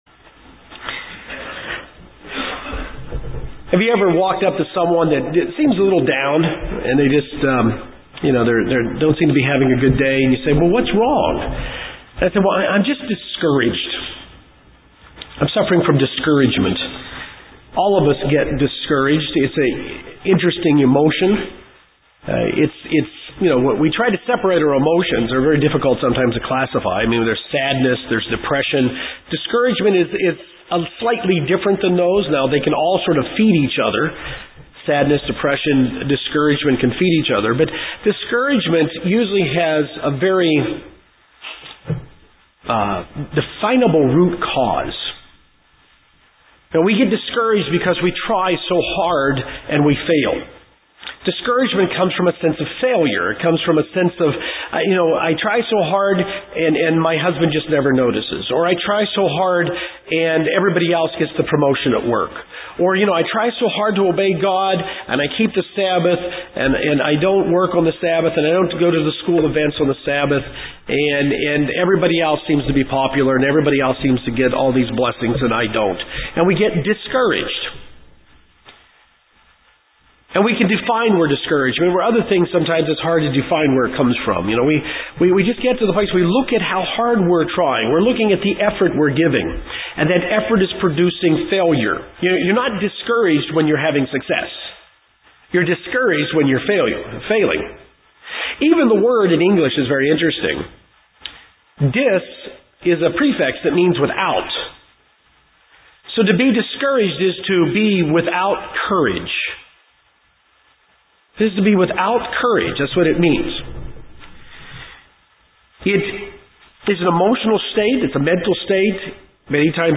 In this sermon we learn how to deal with discouragement.